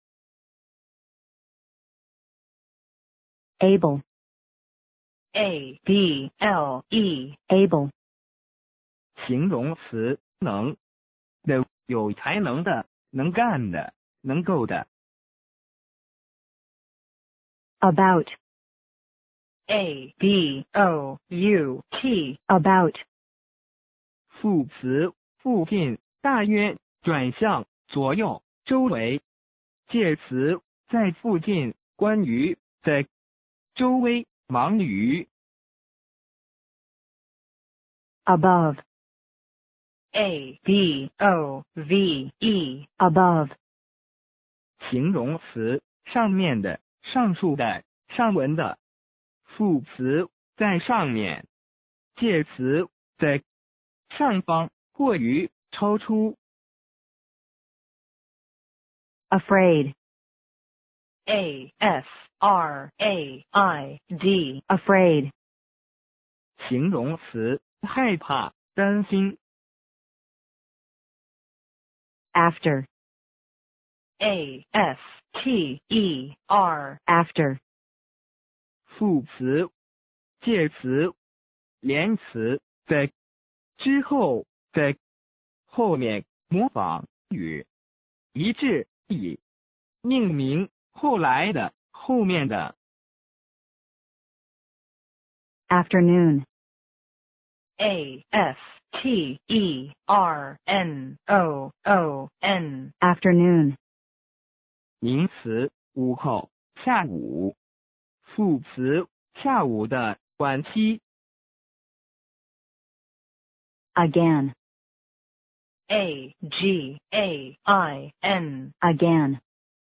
初级部分的mp3朗读了单词的拼写/词性/中文解释，可以不用书进行复习)：